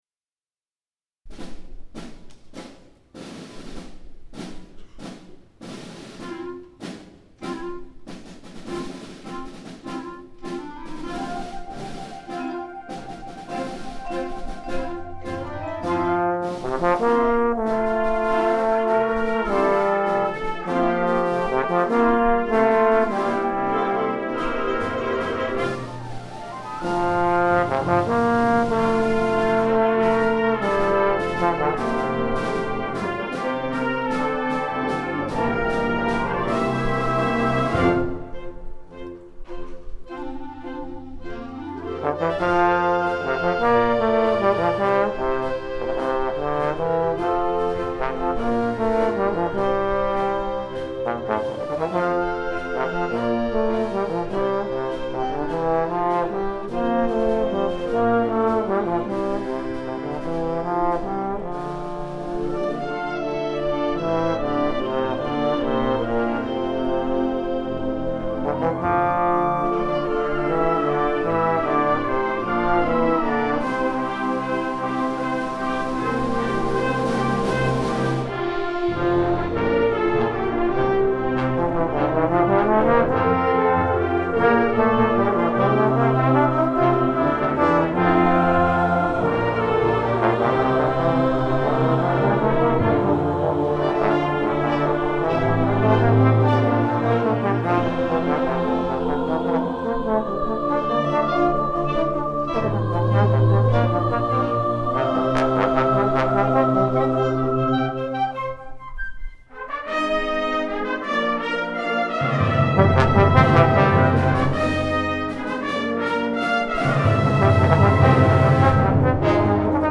Concerto for Bass Trombone, Symphonic Band & Choir